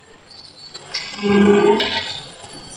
One investigator ventured in alone and began an EVP session there. At some point during his visit, he clearly hears a voice in the otherwise empty room ask for his name and he immediately responds in kind.